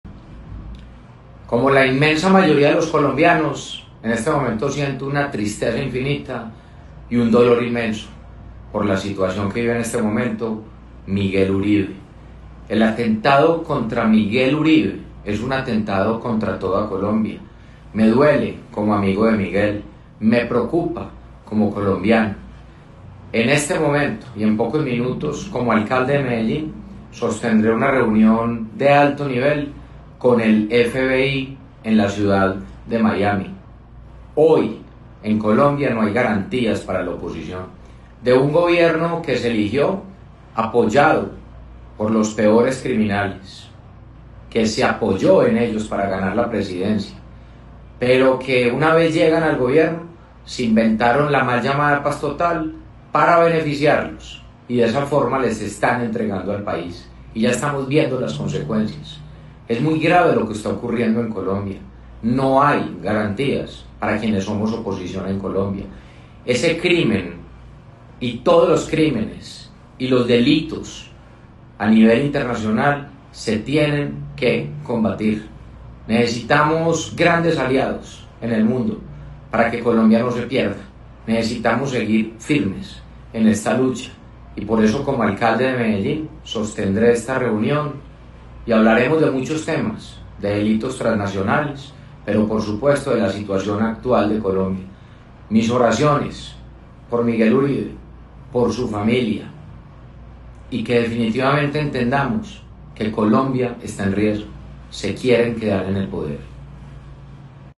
Declaraciones-del-alcalde-de-Medellin-Federico-Gutierrez.mp3